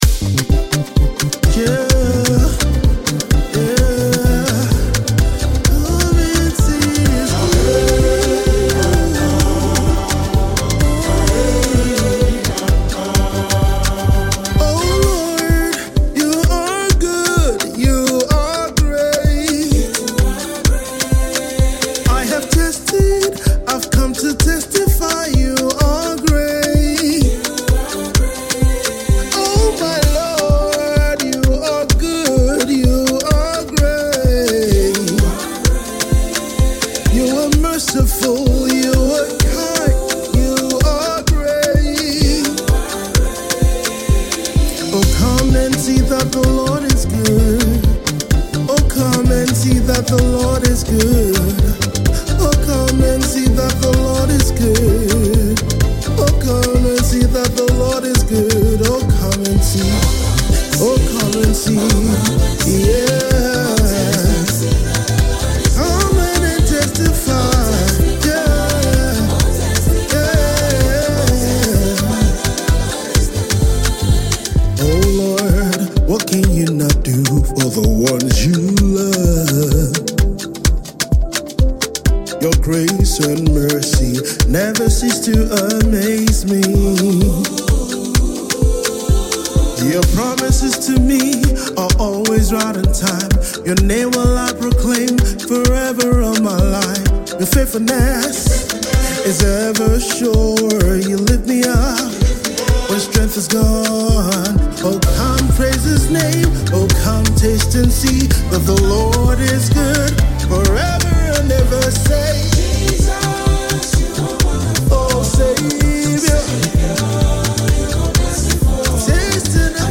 A dynamic gospel artist